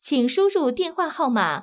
ivr-please_enter_the_phone_number.wav